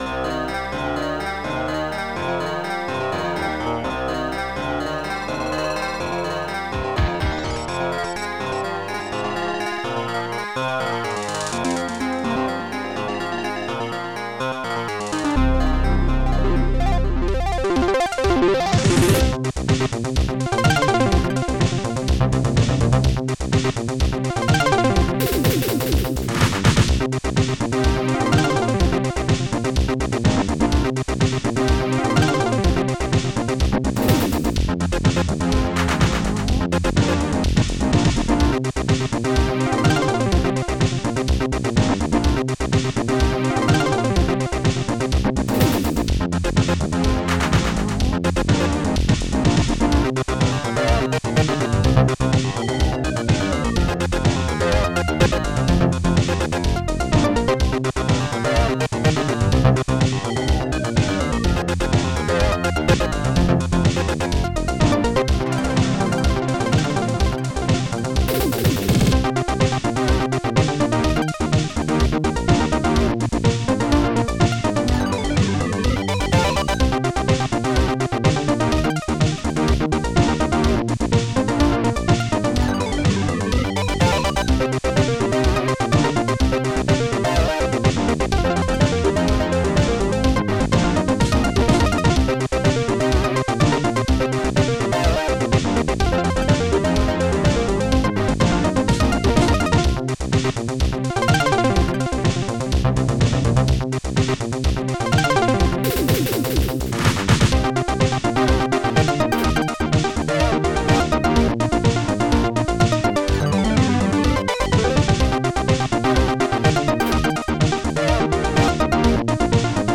st-04:ANIMATE-KICK
st-01:Snare5
st-04:ANIMATE-CLAP